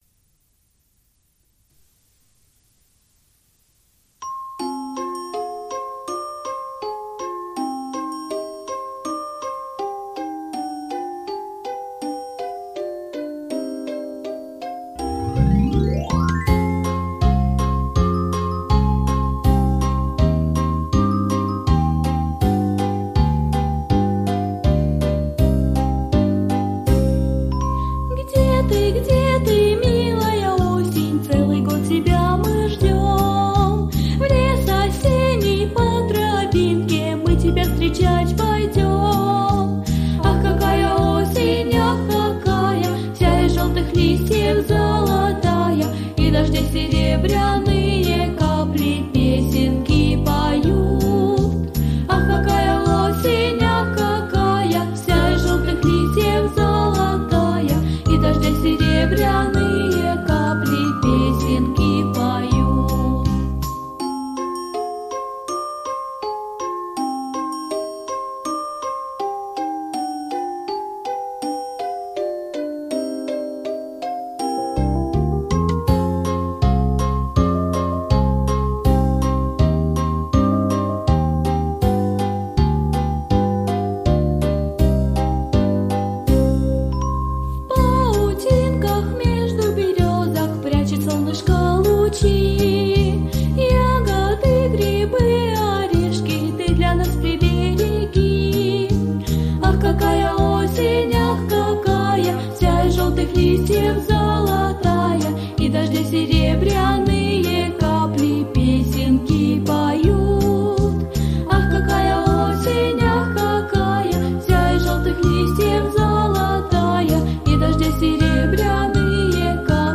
Песенки про осень